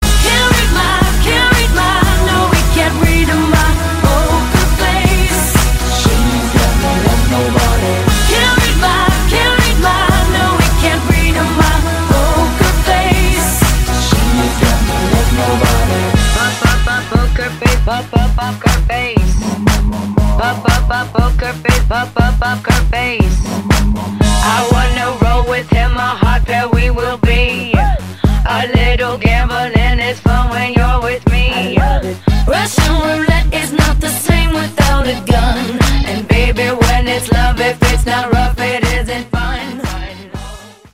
Tonos de canciones del POP